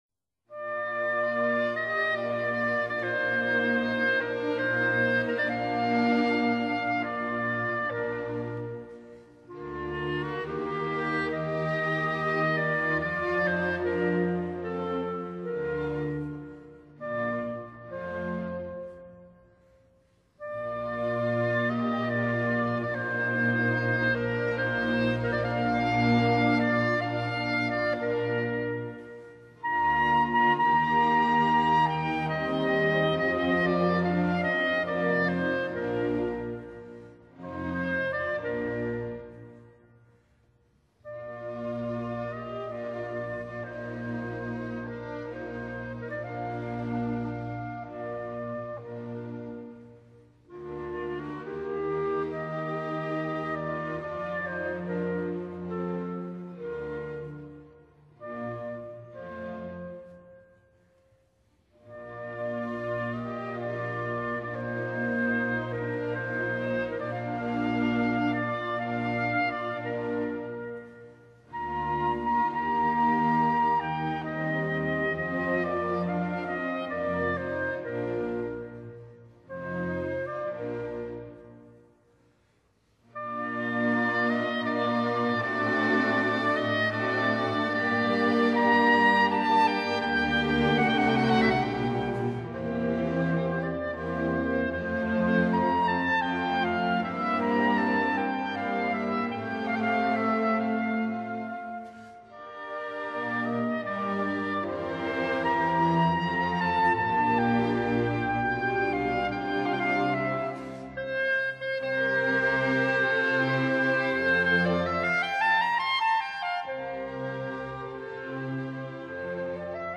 Concerto for Clarinet and Orchestra in E flat major *
(黑管協奏曲)